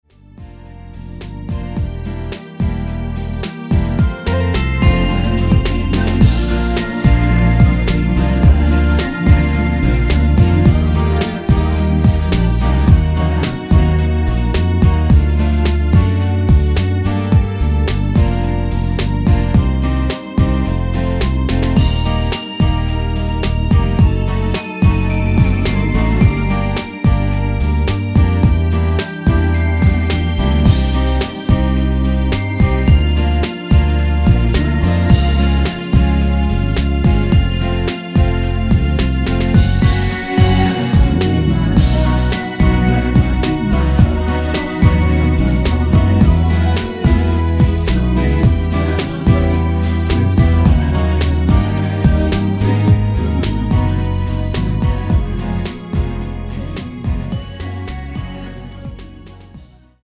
반키(-1) 내린코러스 포함된 MR 입니다.
Bb
앞부분30초, 뒷부분30초씩 편집해서 올려 드리고 있습니다.